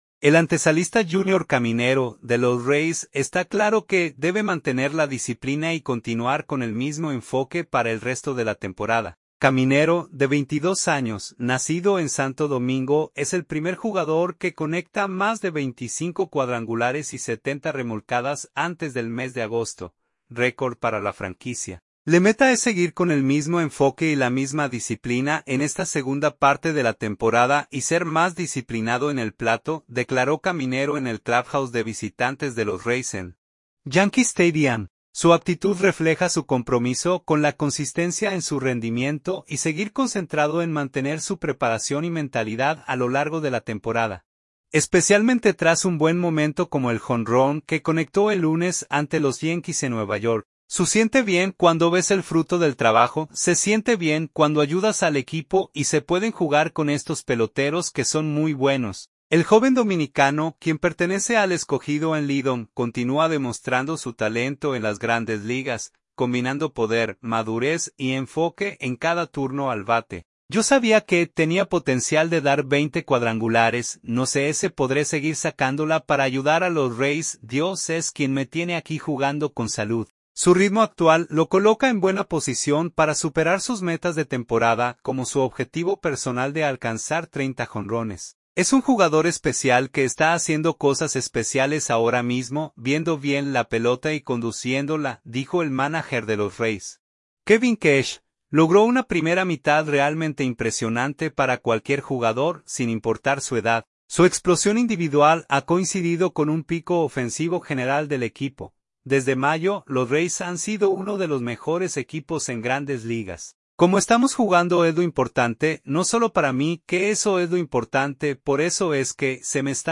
“Le meta es seguir con el mismo enfoque y la misma disciplina en esta segunda parte de la temporada y ser más disciplinado en el plato” declaró Caminero en el clubhouse de visitantes de los Rays en Yankee Stadium.